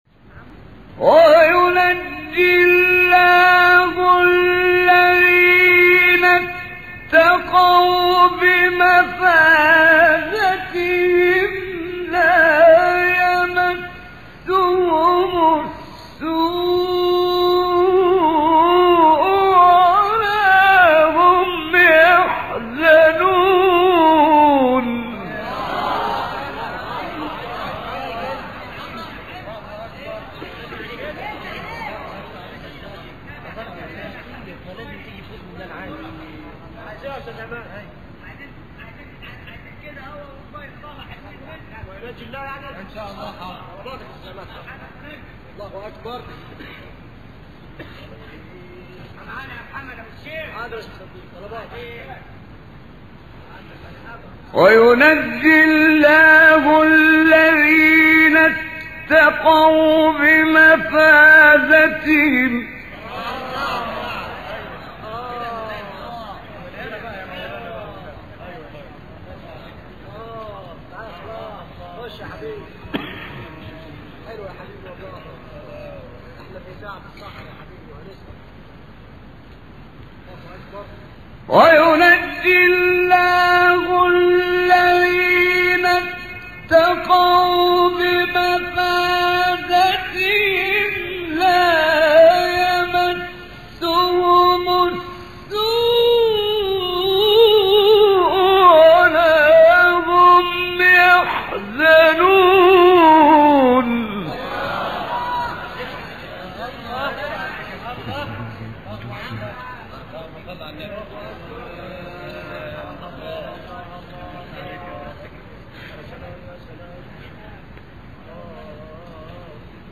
سوره : زمر آیه: 61-63 استاد : محمد عمران مقام : حجاز قبلی بعدی